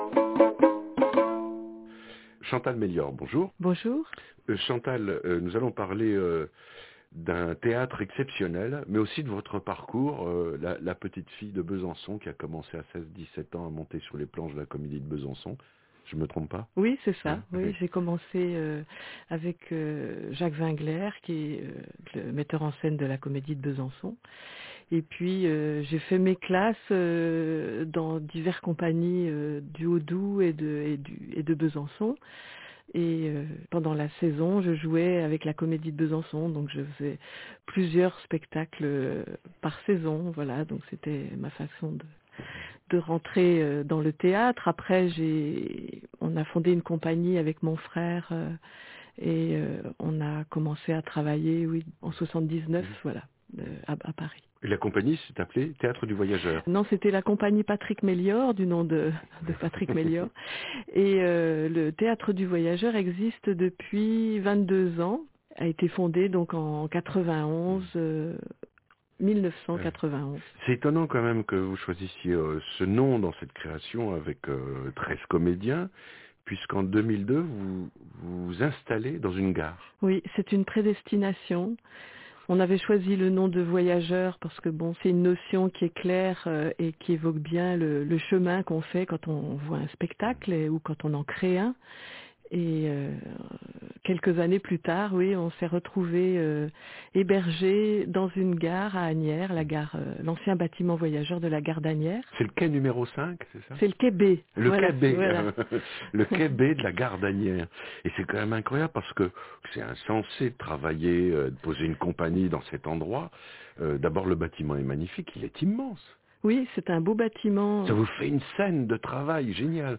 interview-fi2014.mp3